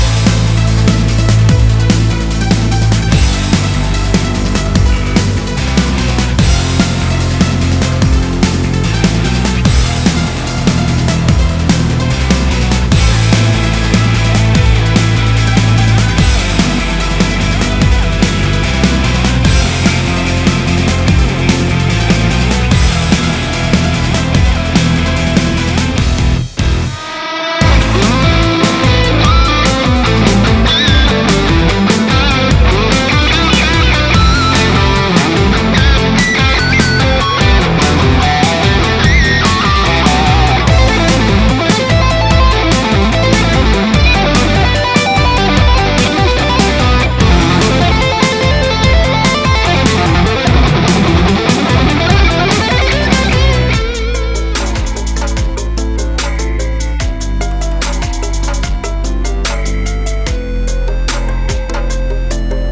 гитары